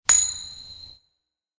flip sound.
flip.ogg